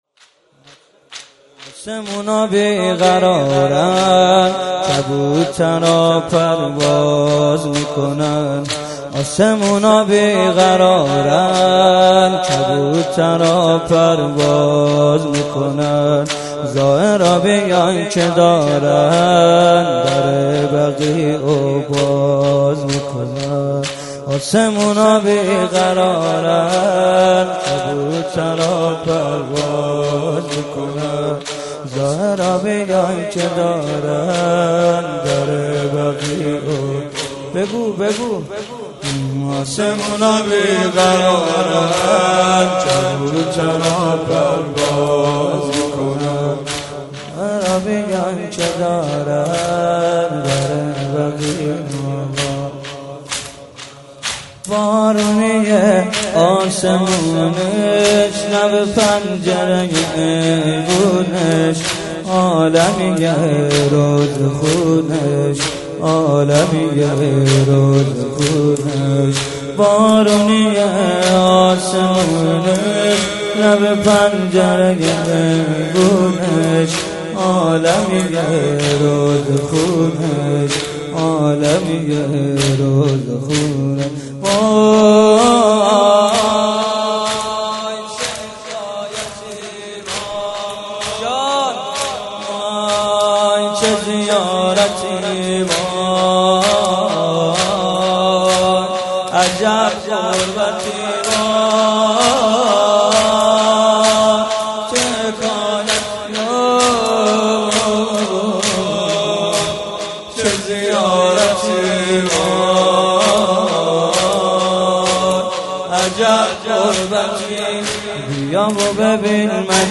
مناسبت : شب ششم رمضان
قالب : زمینه
03.sineh zani.mp3